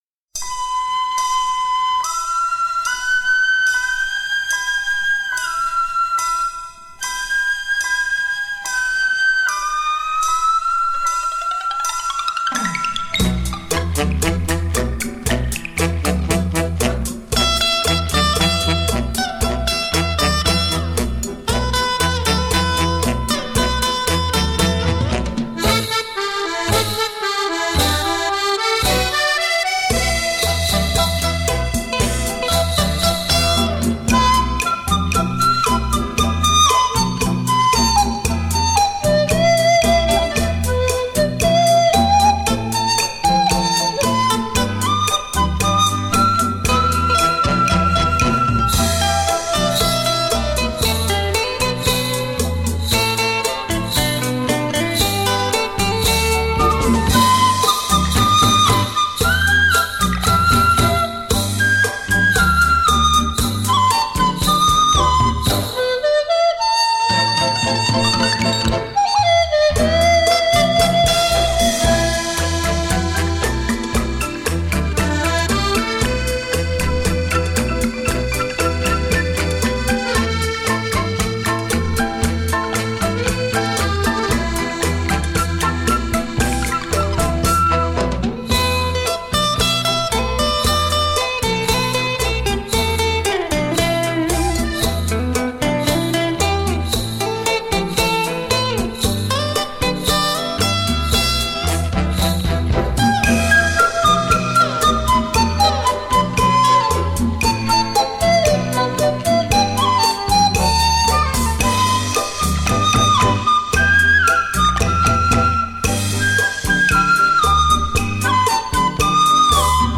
笛、电吉他